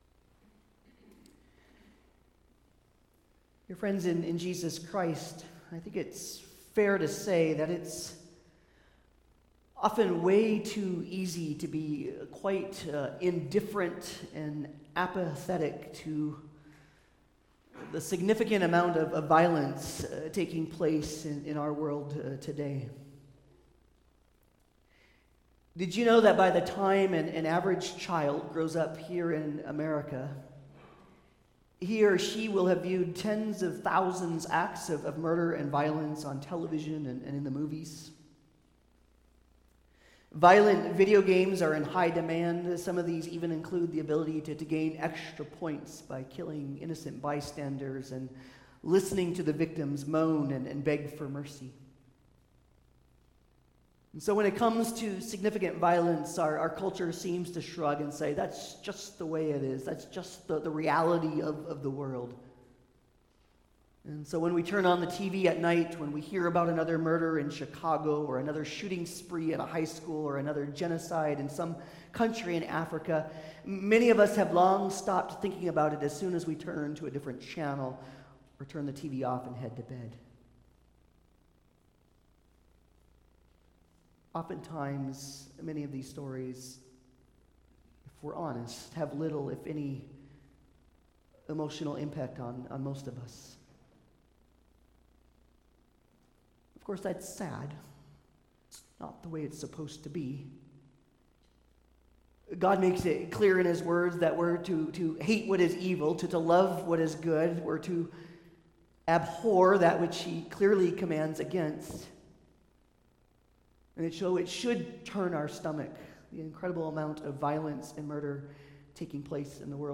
Commandments Passage: Exodus 20:13, Matthew 5:21-26 Service Type: Sunday Service